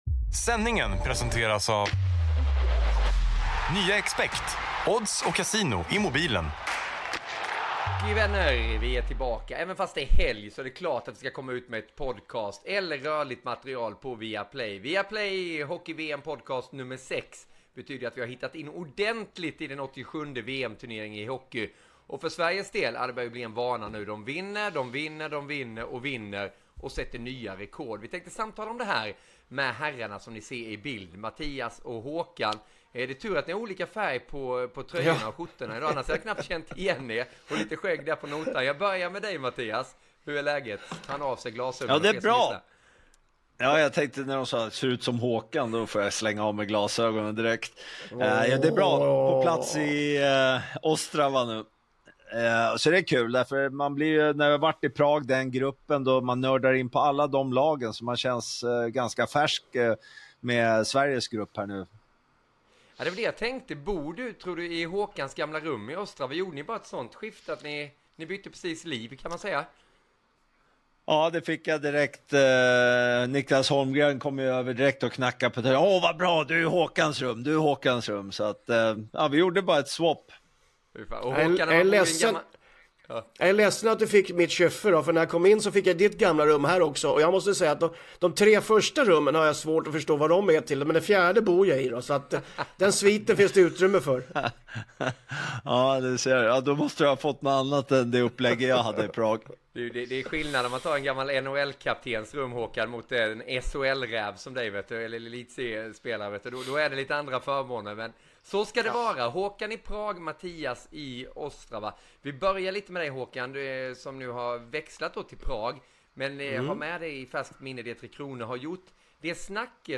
Intervjuer och rapporter både från Prag och Ostrava.